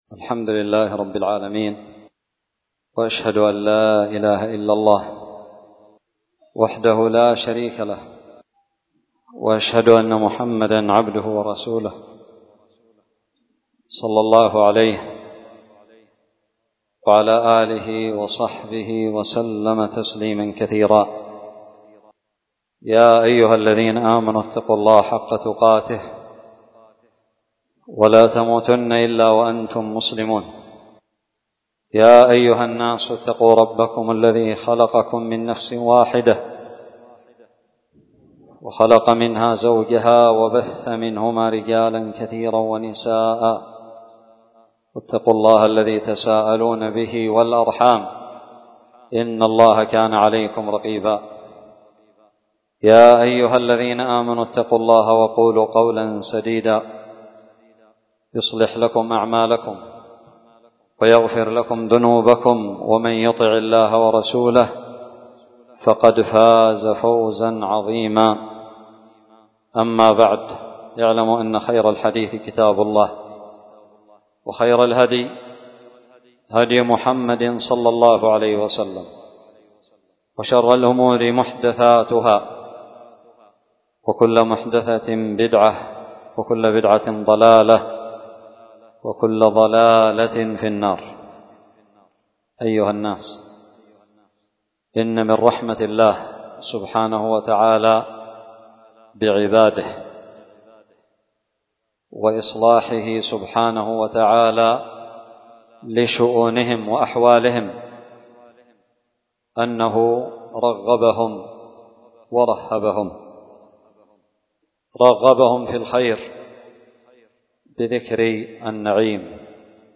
خطبة الخسوف عام 1447 شهر ربيع الأول
ألقيت بدار الحديث السلفية للعلوم الشرعية بالضالع
خطبة-الخسوف-عام-1447-شهر-ربيع-الأول.mp3